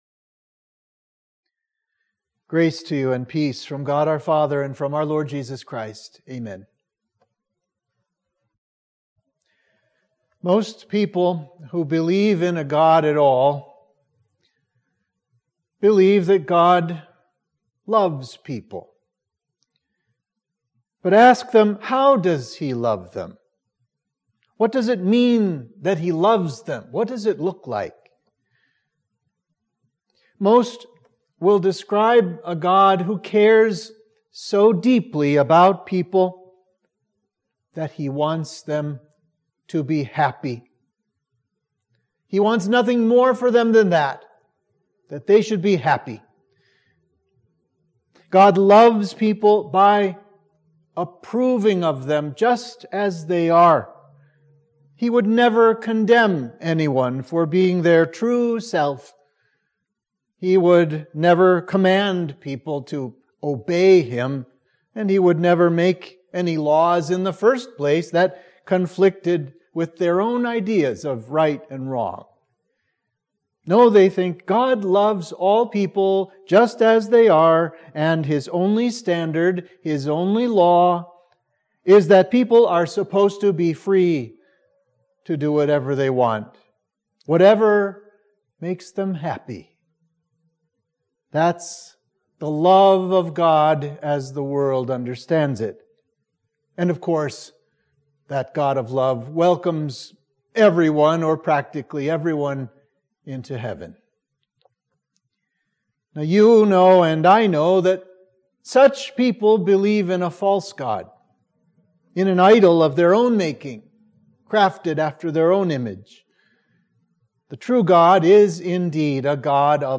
Sermon for Midweek of Pentecost